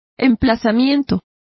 Complete with pronunciation of the translation of citation.